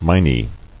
[mi·nae]